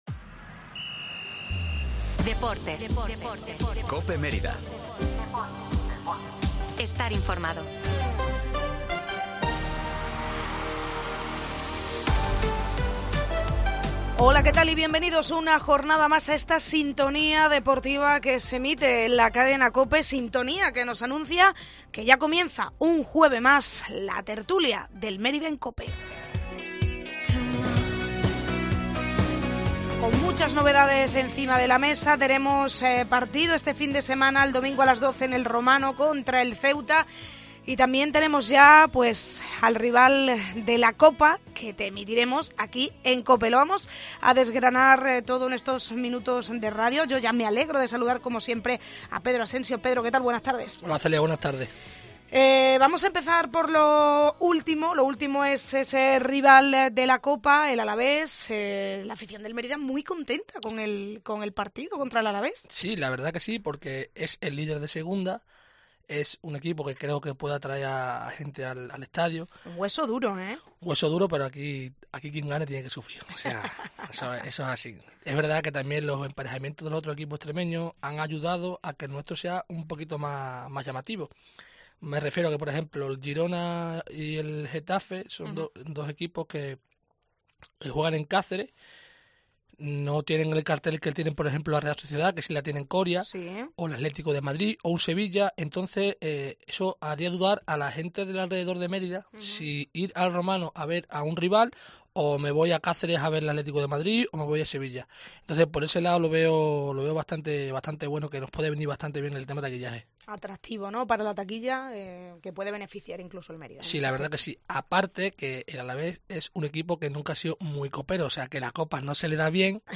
La tertulia del Mérida en COPE